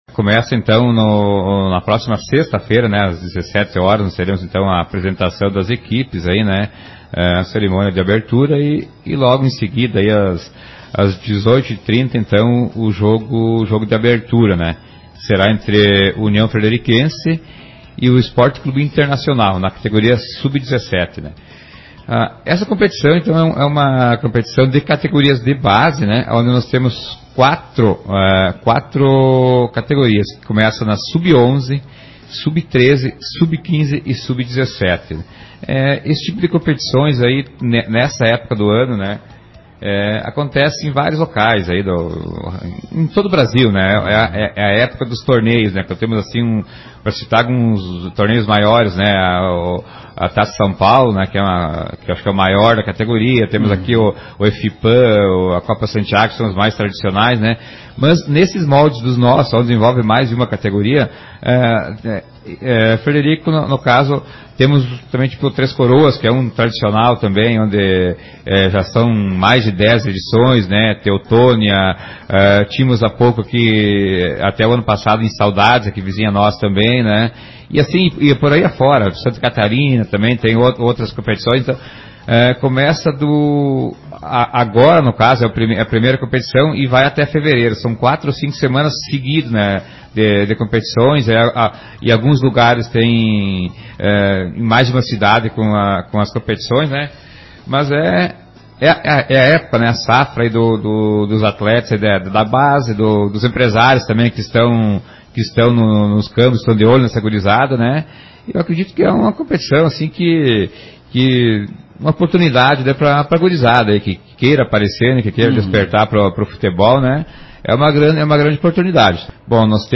O Secretário Municipal de Esporte, Vanderlei Colle, comenta sobre a competição: